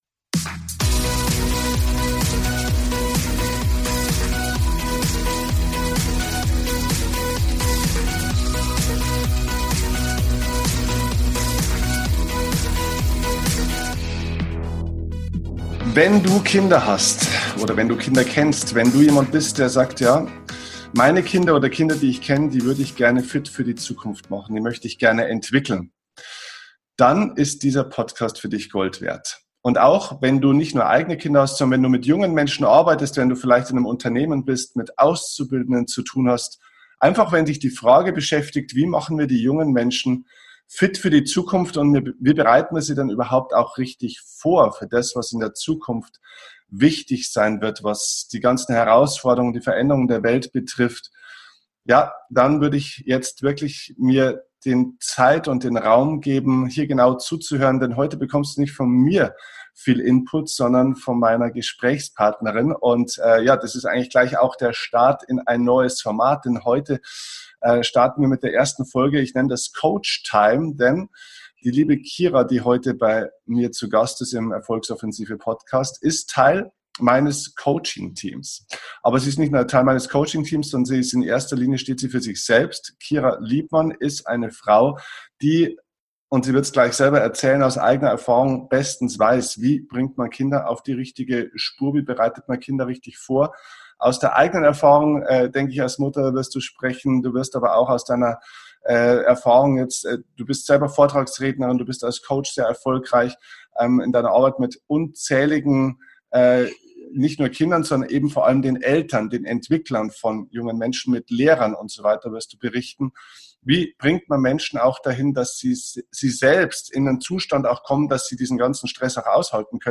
Coach-Talk